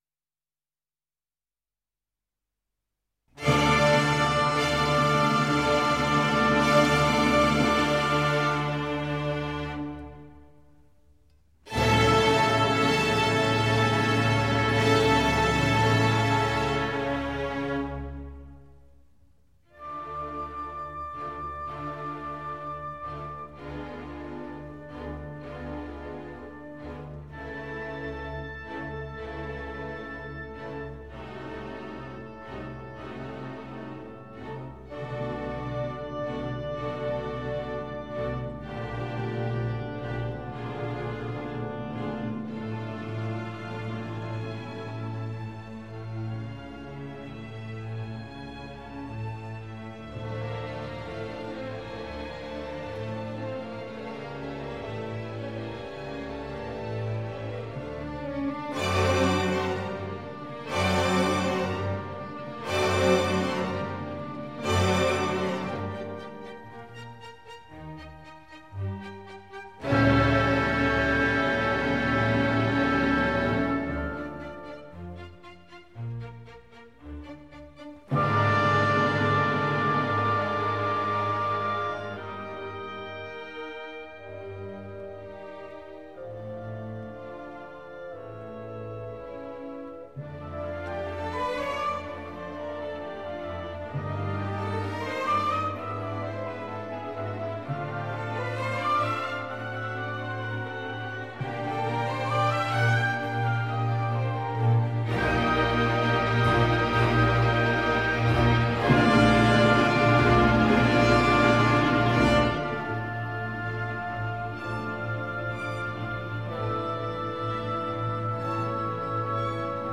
Opera buffa
1706 - 1850 (Baroque, Classical)